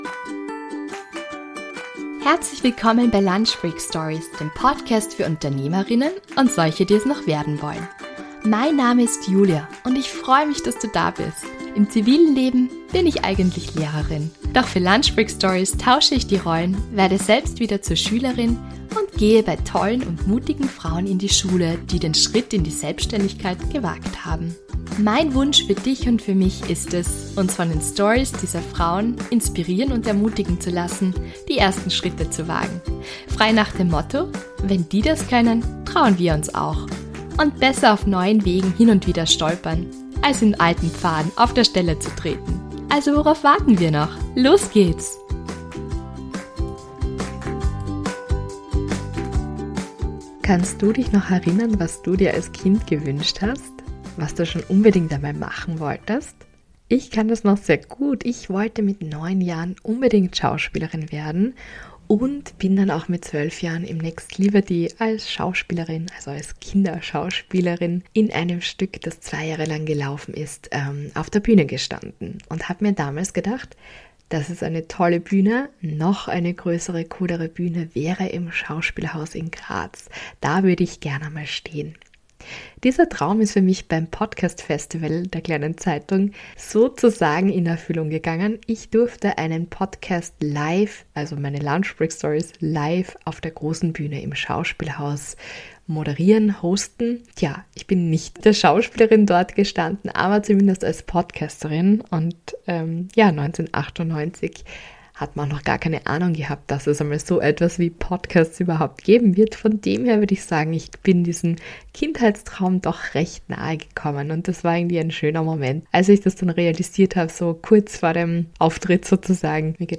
LIVE beim Kleine Zeitung Podcast Festival